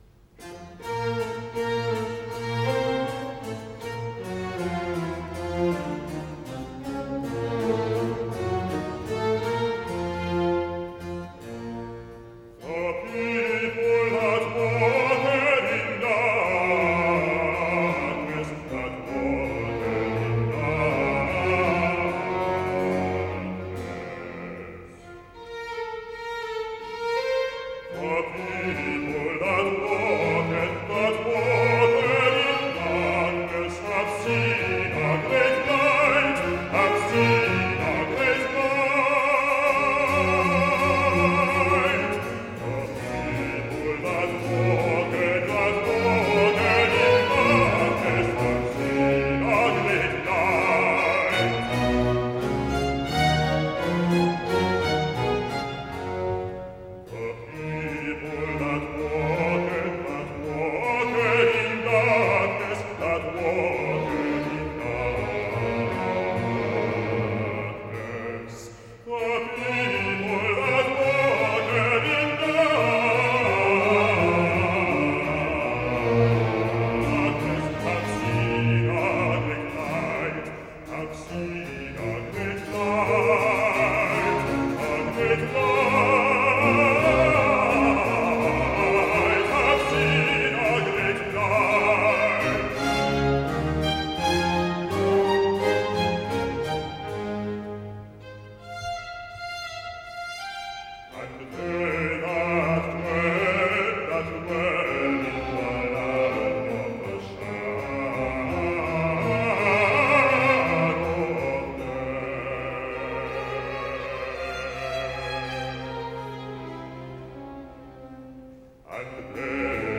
Aria-bass